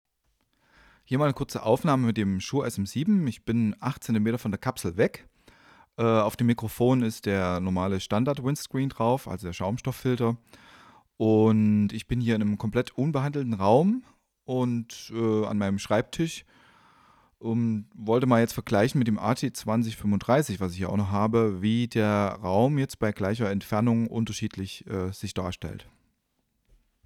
Nur mal ein kleiner, "nicht akademischer" schneller Vergleich zwischen Stollen und Gänsebraten Kein Limiter, EQ oder Komp. Lediglich ein Manley Tube Preamp davor, Lowcut bei 80Hz, Verstärkung nach Gehör eingestellt.